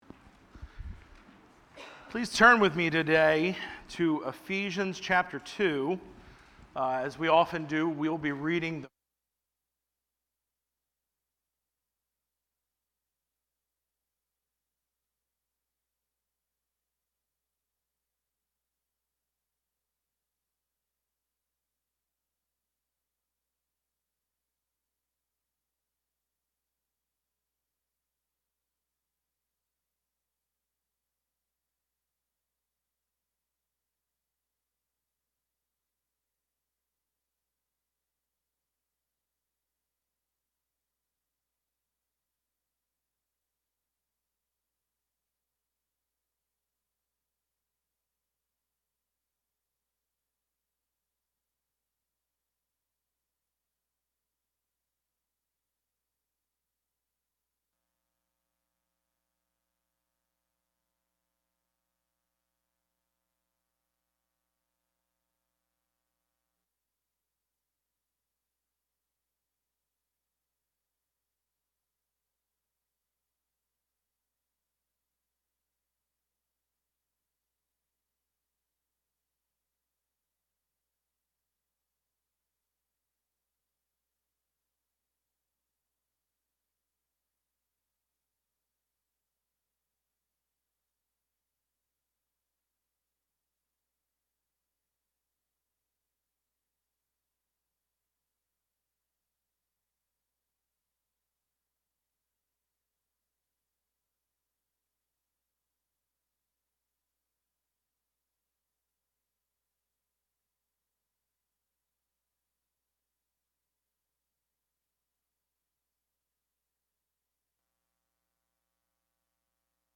A message from the series "Exposition of Ephesians."